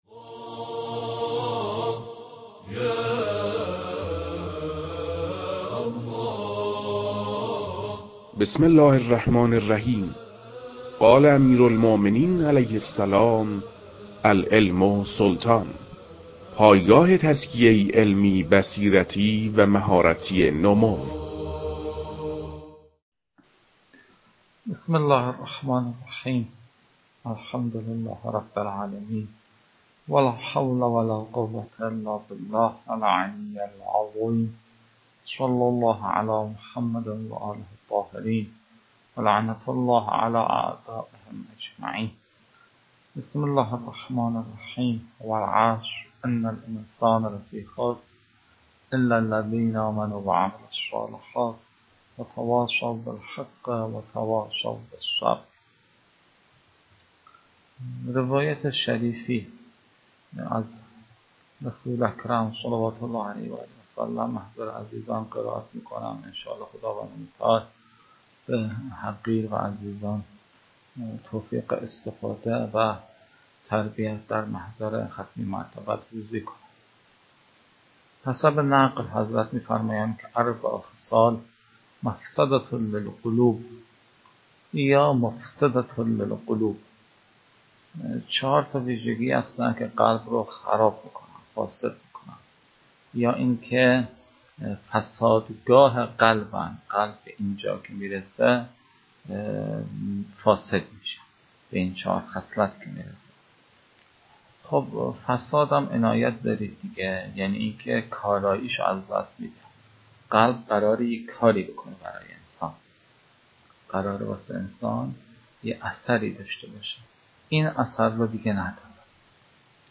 در این بخش، فایل های مربوط به تدریس مباحث تنبیهات معاطات از كتاب المكاسب متعلق به شیخ اعظم انصاری رحمه الله